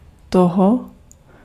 Ääntäminen
Ääntäminen France: IPA: [la] Haettu sana löytyi näillä lähdekielillä: ranska Käännös Ääninäyte 1. to {n} 2. toho 3. tomu Suku: f .